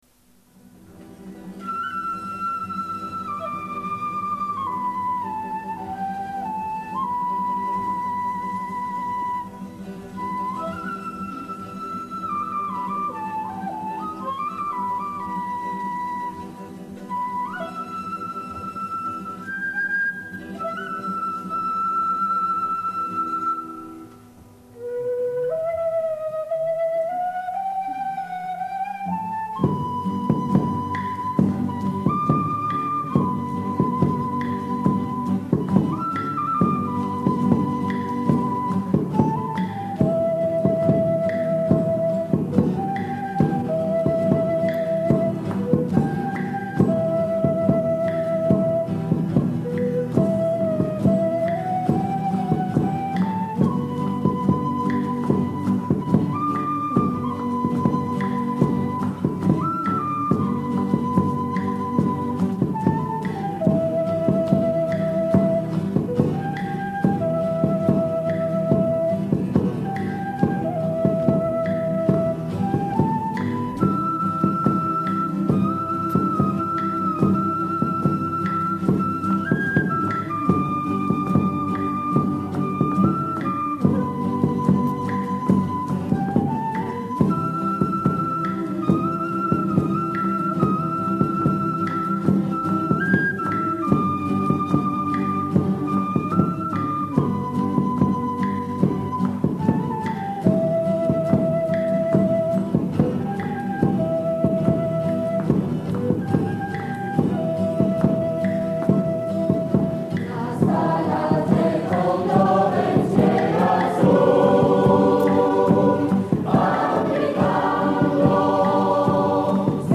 Concert "A Coeur Joie" 1 Juin 1979 CLUNY avec la Chorale "Clunysia",
l'Ensemble Vocal Mixte et l'Ensemble Vocal de l'ENSAM
Extaits du Concert de CLUNY